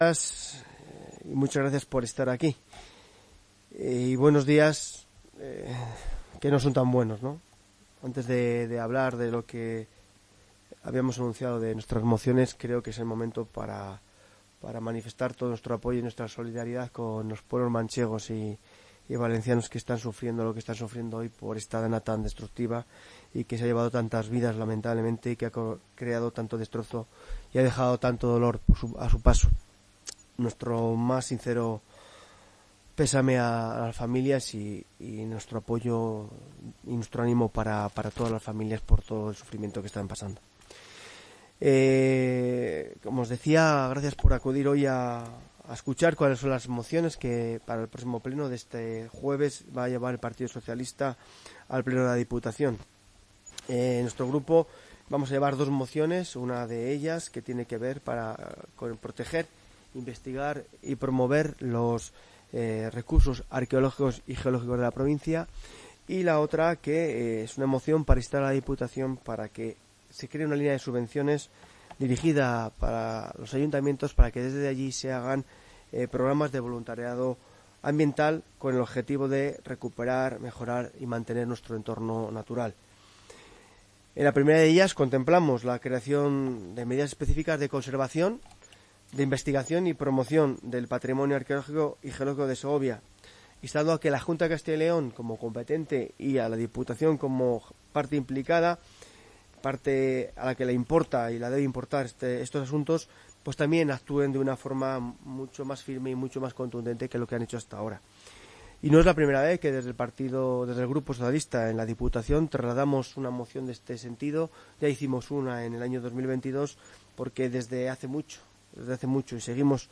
El portavoz del Grupo Socialista, Máximo San Macario, ha comparecido en rueda de prensa para explicar ambas mociones.